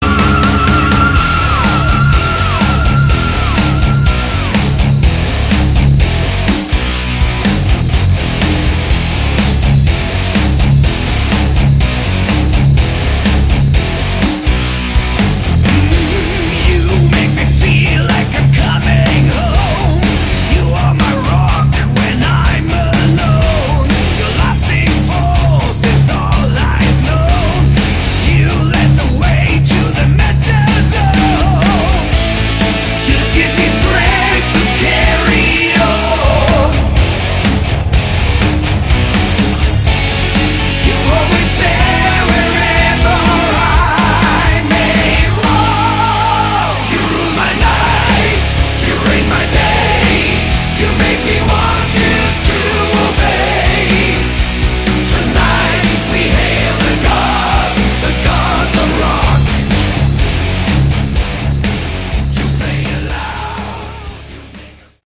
Category: Hard Rock
This time more of a power metal feel.